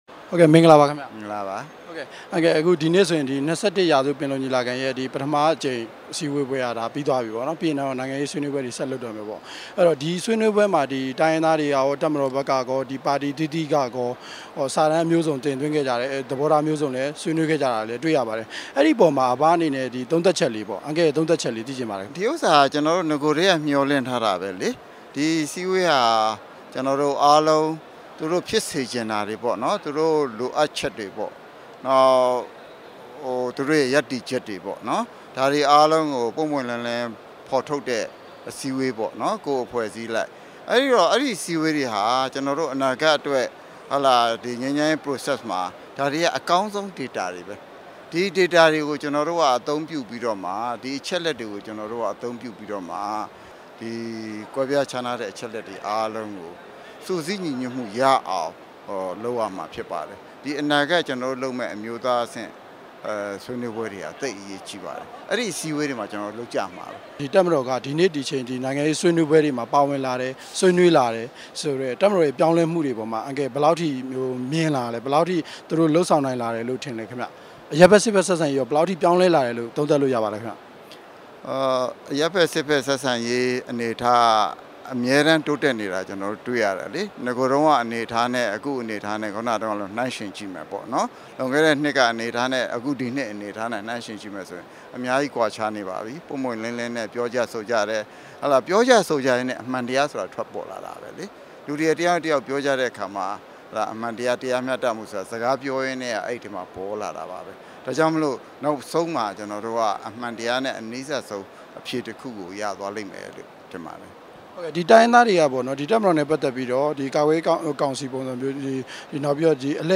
ဝန်ကြီးဟောင်း ဦးအောင်ကြည်နဲ့ တွေ့ဆုံမေးမြန်းချက်